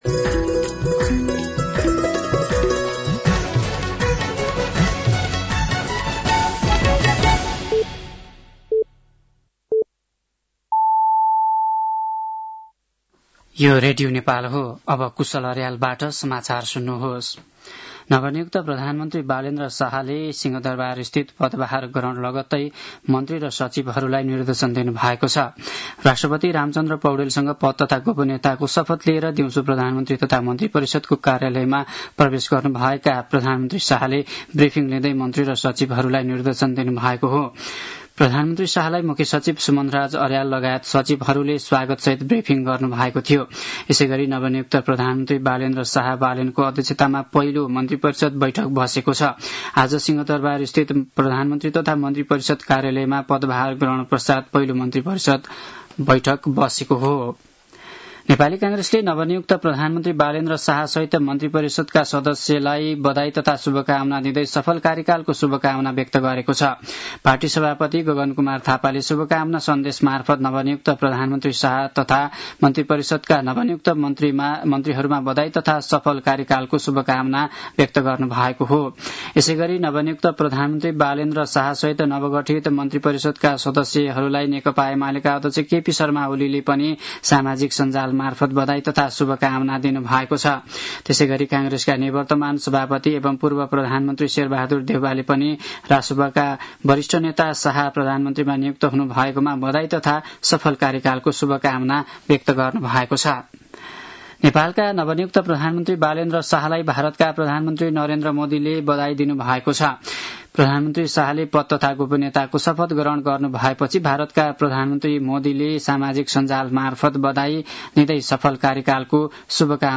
साँझ ५ बजेको नेपाली समाचार : १३ चैत , २०८२